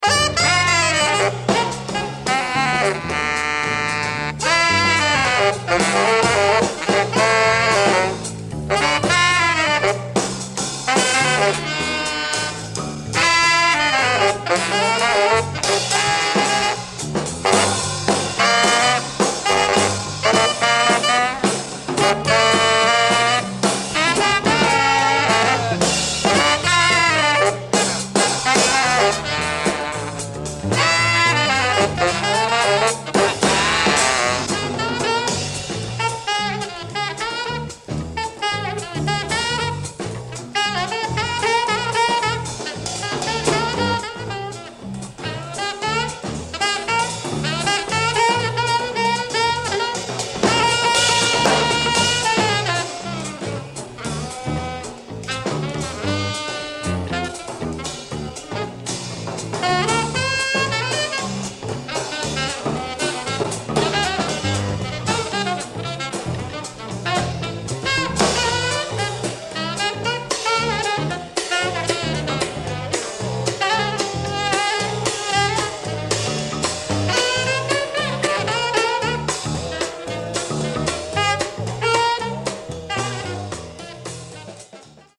A live quartet date from the South African double bassist.